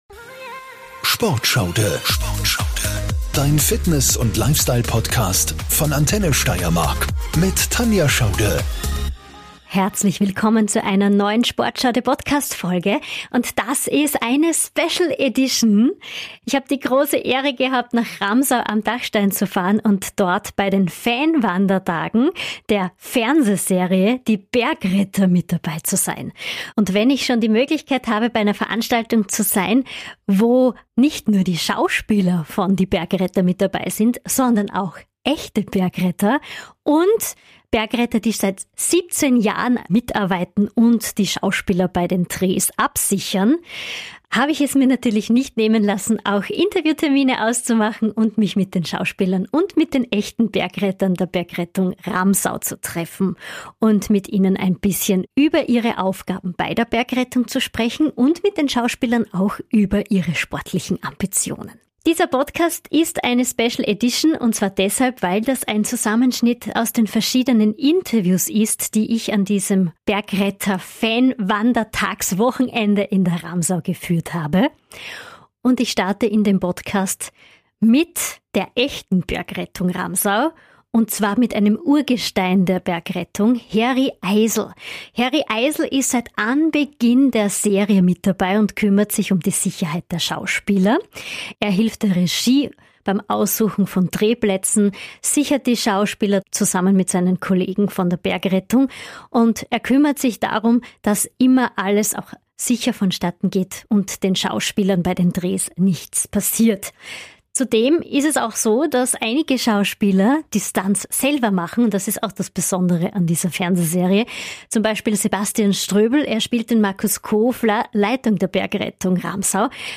Unsere SportSchauDe hat sich unter die Fans gemischt und hat mit den Hauptdarstellern Sebastian Ströbel (als Markus Kofler), Markus Brandl (als Tobias Herbrechter), Robert Lohr (als Michael "Michi" Dörfler), Michael Pascher (als Rudi Dolezal) und Stefanie von Poser (als Emilie Hofer) über ihre sportlichen Vorlieben gesprochen, wie sie sich für die Serie fit halten und was ihnen an Ramsau am Dachstein und der Steiermark besonders gut gefällt.